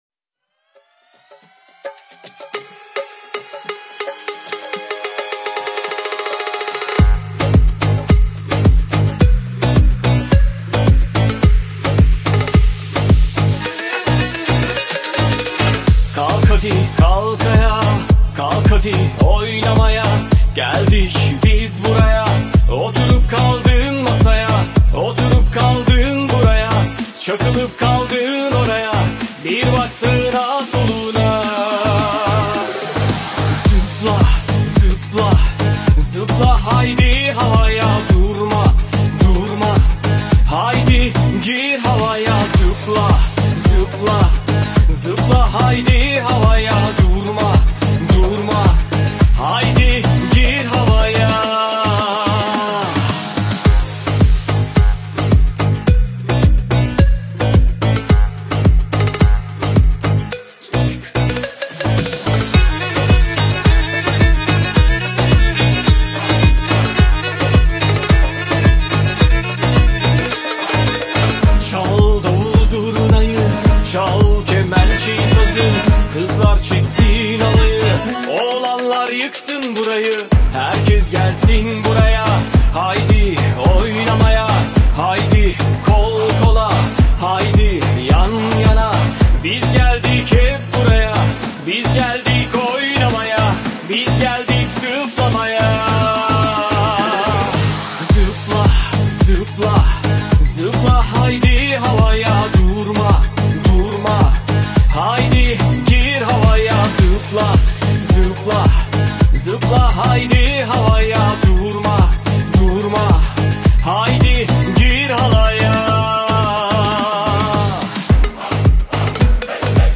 Турецкая песня